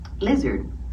lizard-button.mp3